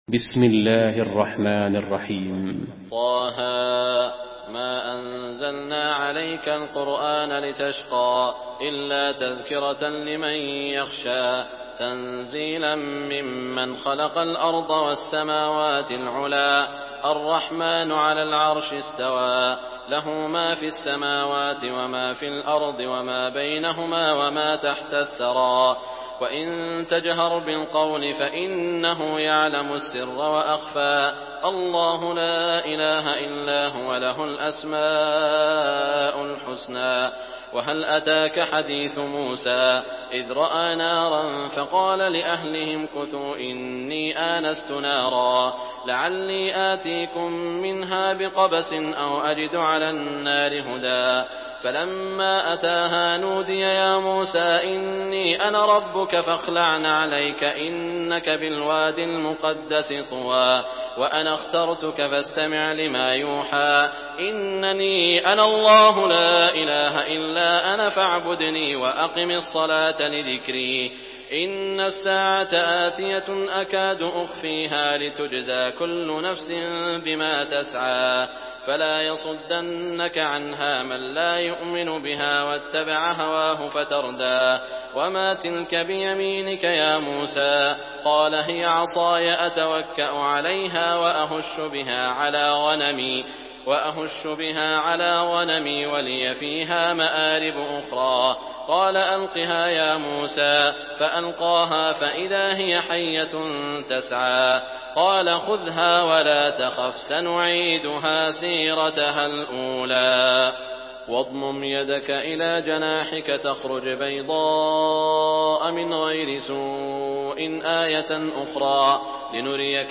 Surah Taha MP3 by Saud Al Shuraim in Hafs An Asim narration.
Murattal Hafs An Asim